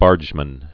(bärjmən)